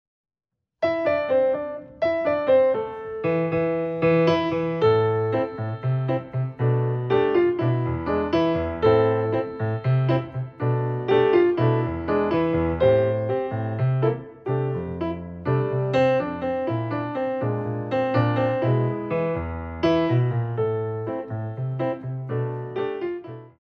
Ballet class music for first years of ballet